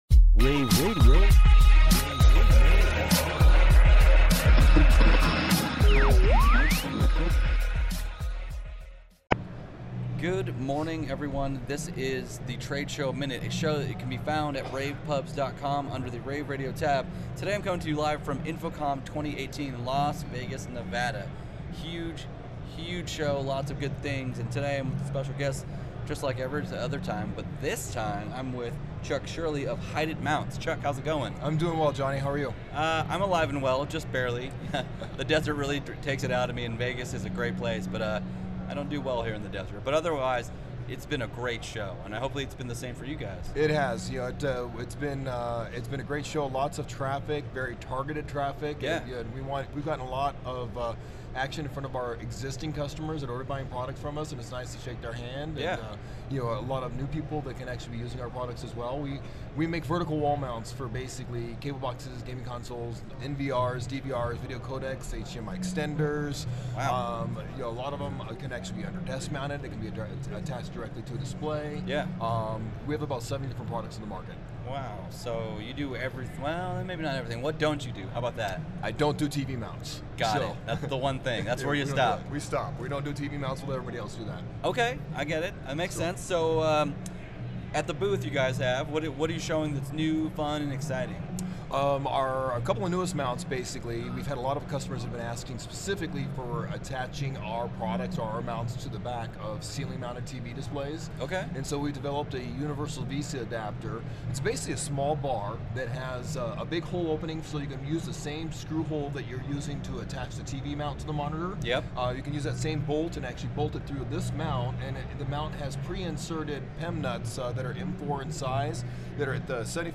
June 9, 2018 - InfoComm, InfoComm Radio, Radio, rAVe [PUBS], The Trade Show Minute,